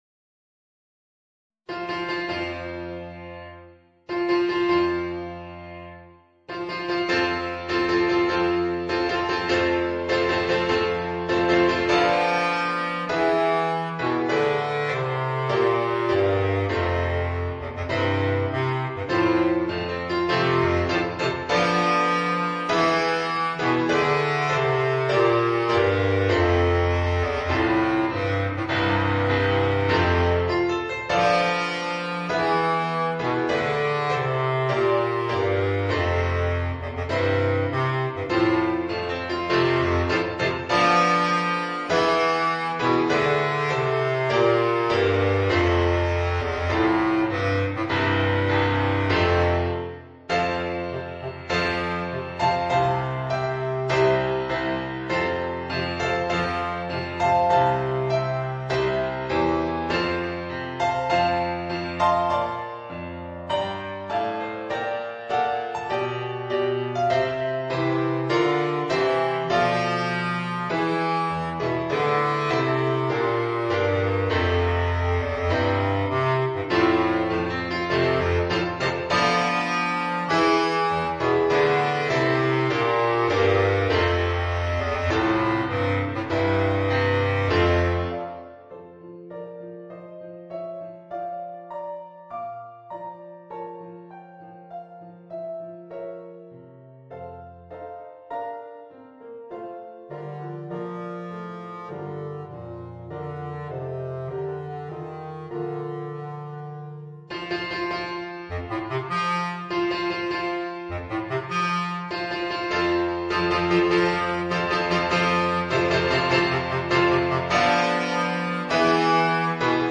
Bass Clarinet and Organ